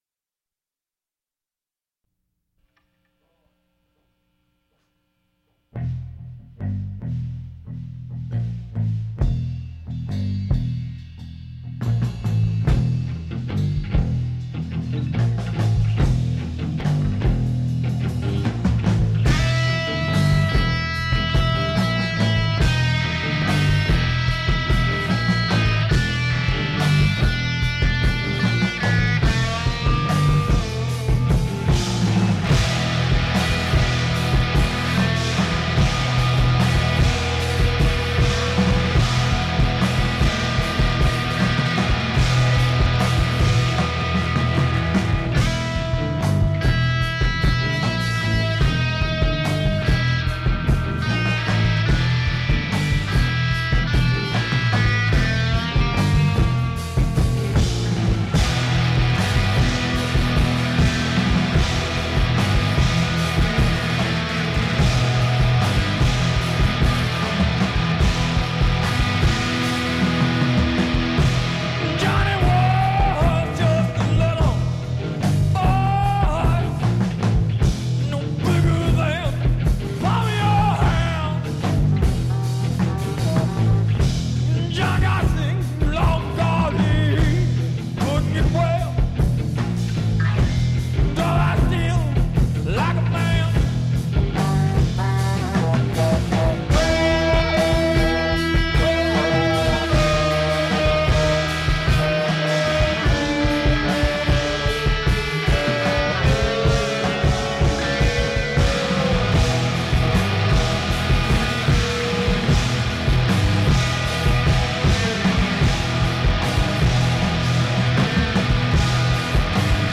Bass, Vocals
Guitar
Sax
Drums